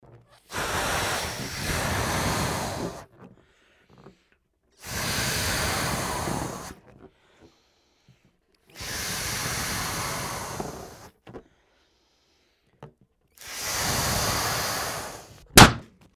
Надувают огромный шарик и он лопает